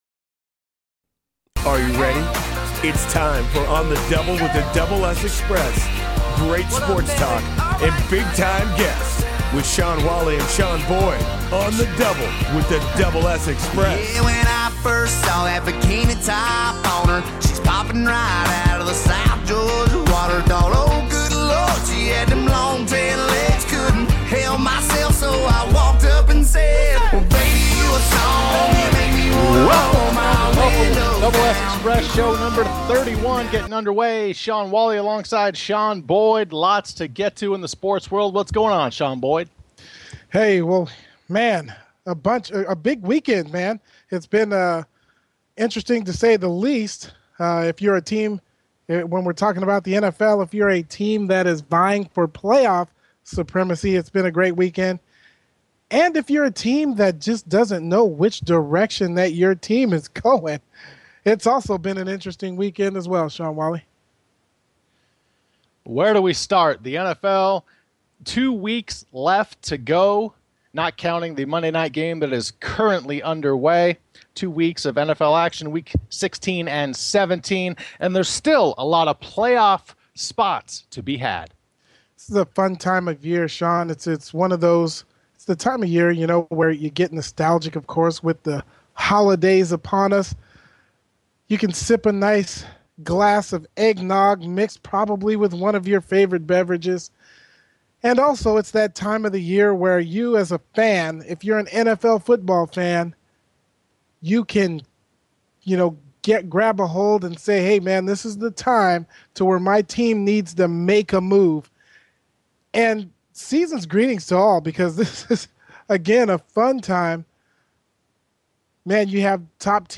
sports talk show